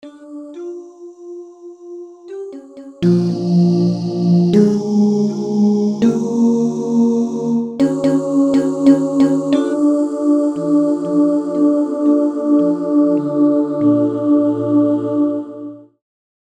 synth voices & a piano